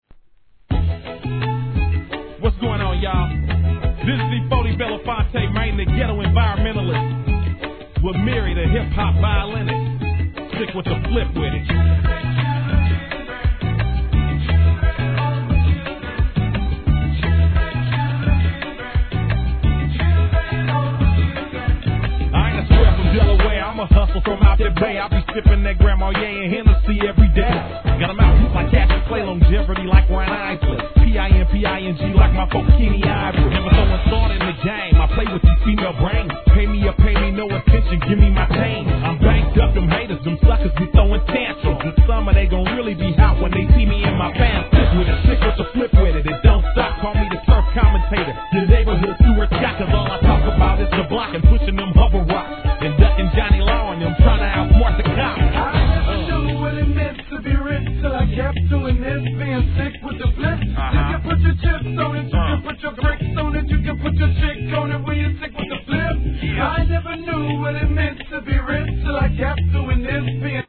HIP HOP/R&B
HIP HOPバイオリニスト!